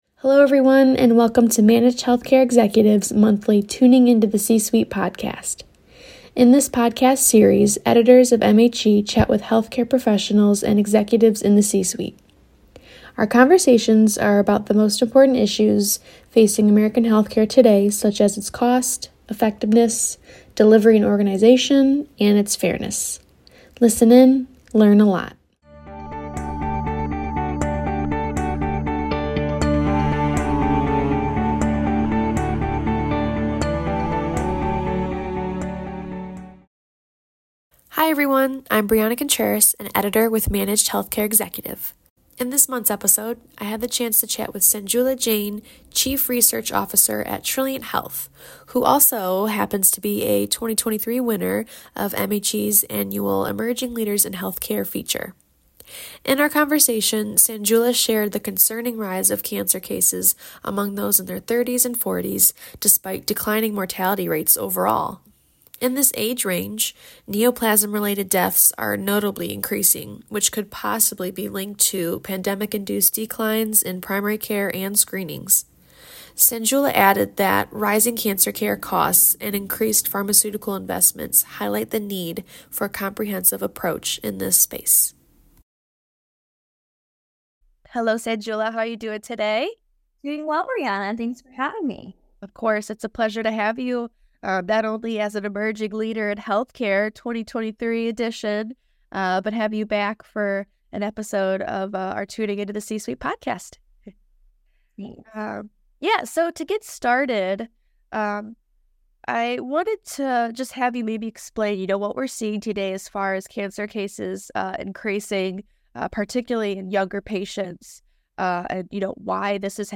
In the conversation